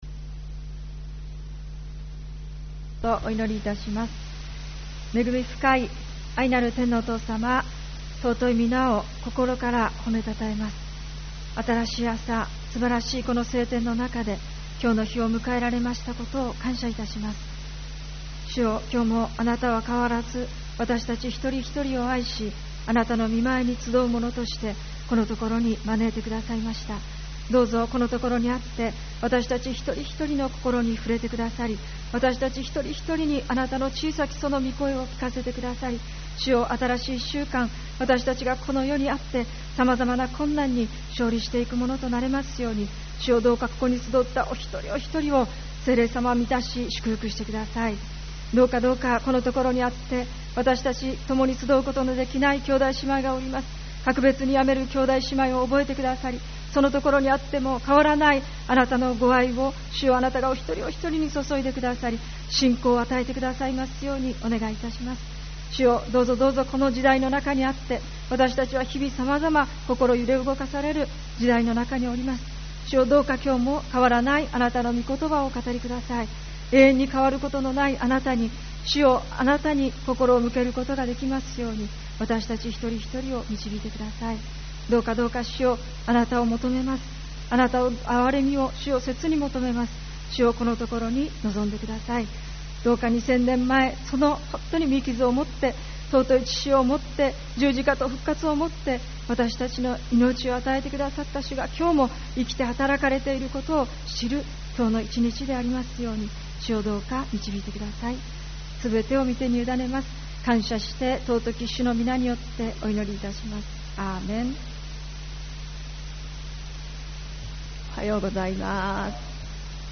主日礼拝 「神を頼りとして生きる